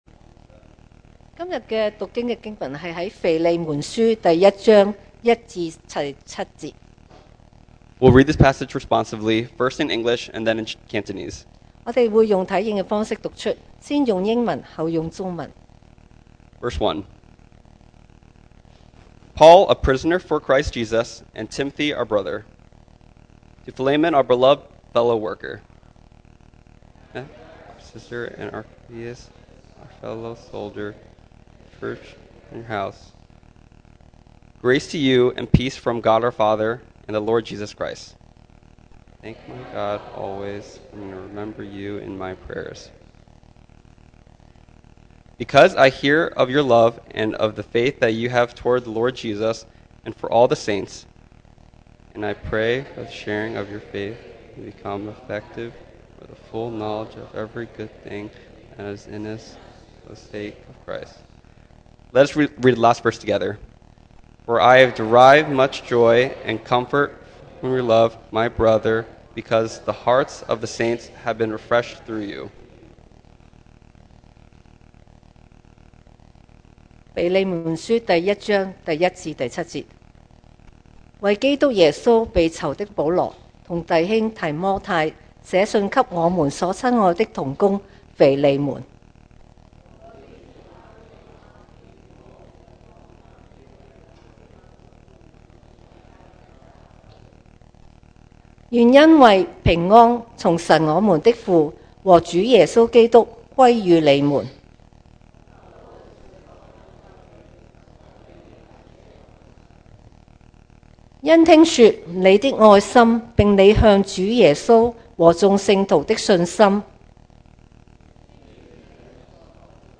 2024 sermon audios
Service Type: Sunday Morning